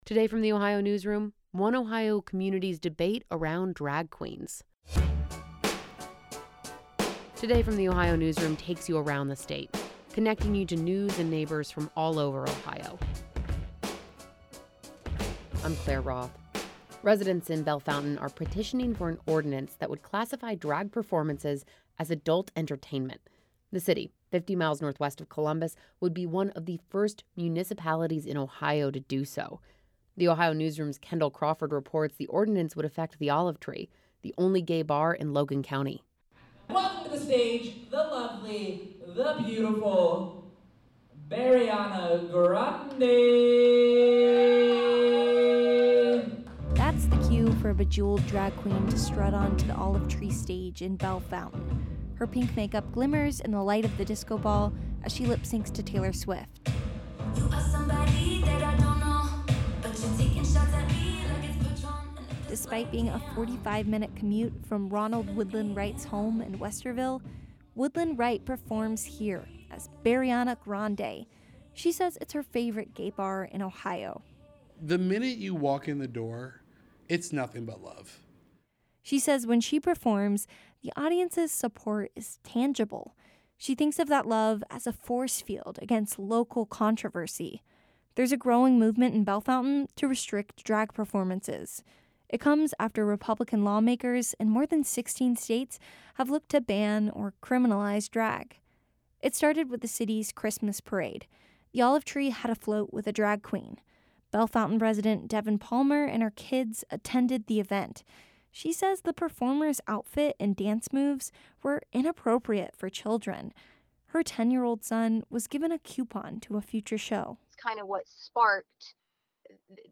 A small crowd cheers on a bejeweled drag queen, as she struts onto a stage at the Olive Tree in Bellefontaine.